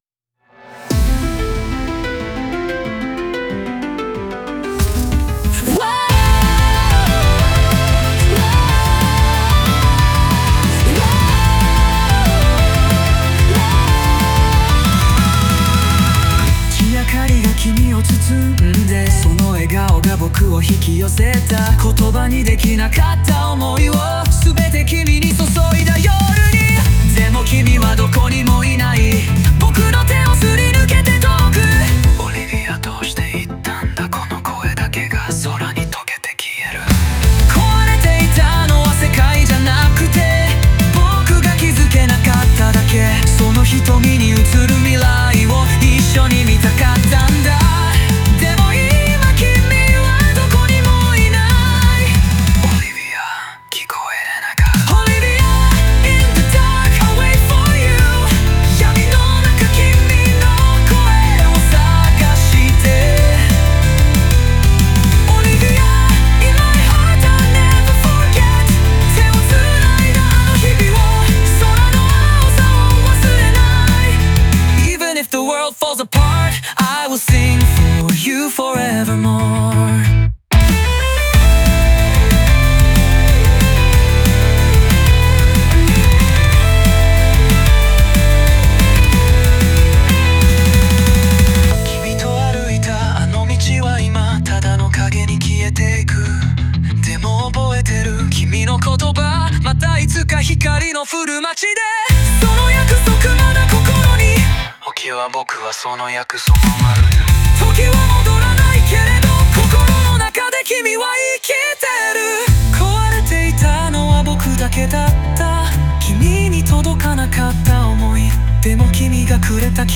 全体的に切なく、心の葛藤と希望が交錯する作品です。